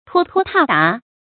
拖拖沓沓 tuō tuō tà tà
拖拖沓沓发音